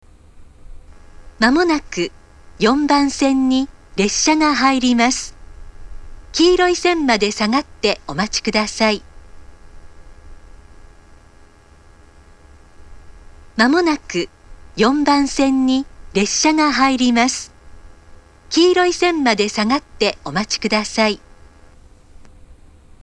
接近放送には発車メロディーと同様の低周波ノイズが被りますが、発車放送ではそれがありません。
接近放送
通過接近放送は2回しか流れなくなりました。